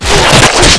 knife_stab_hunter.wav